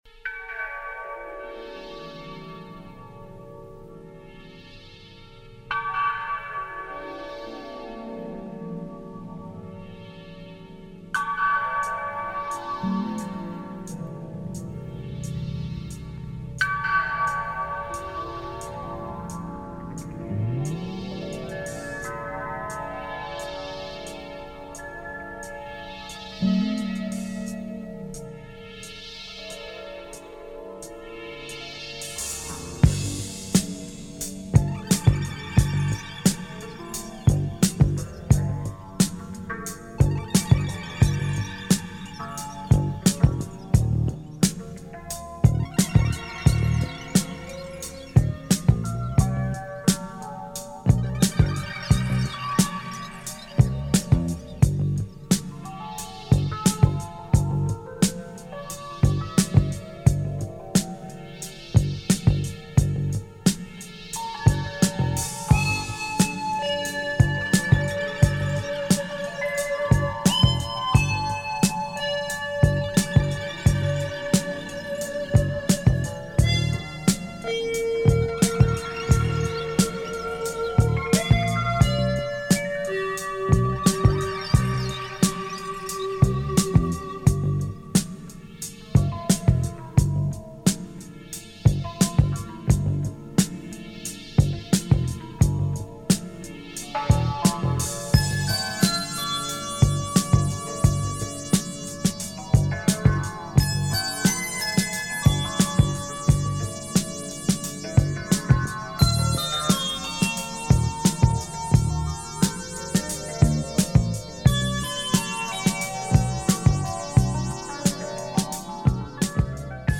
Includes a deep spacy breakbeat